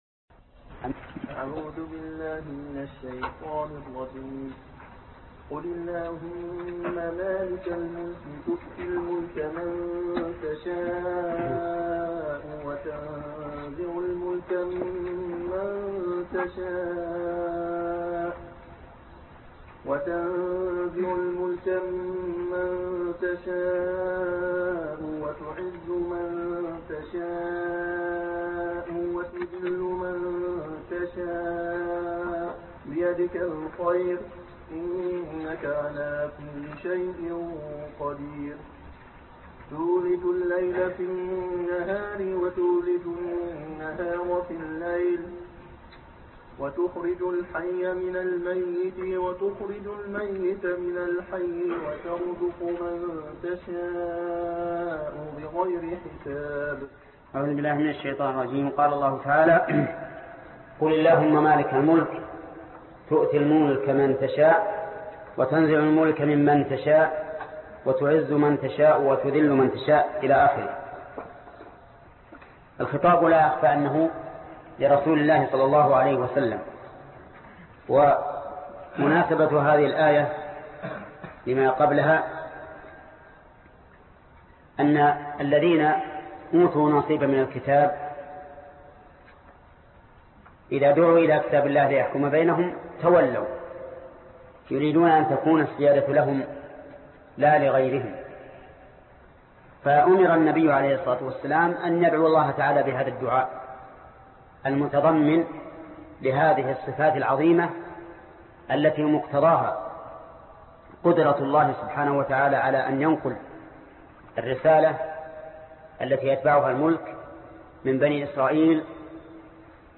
الدرس 51 الآية رقم 26 (تفسير سورة آل عمران) - فضيلة الشيخ محمد بن صالح العثيمين رحمه الله